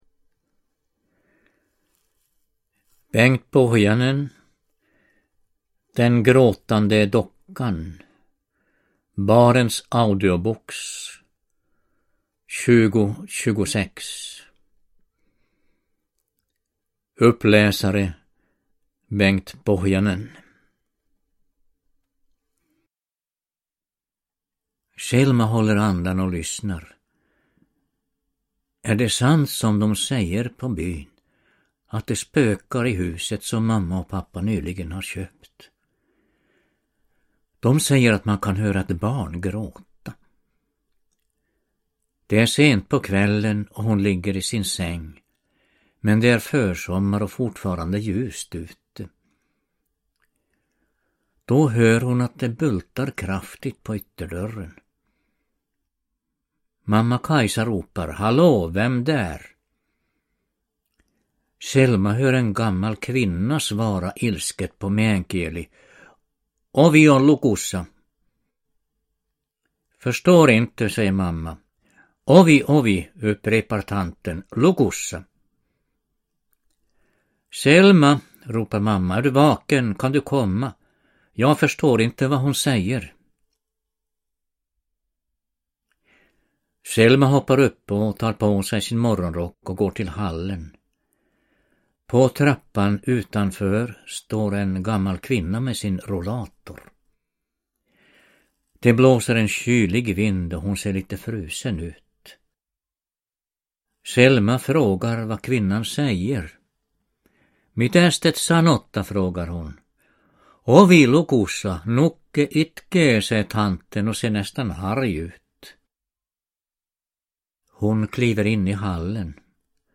Uppläsare: Bengt Pohjanen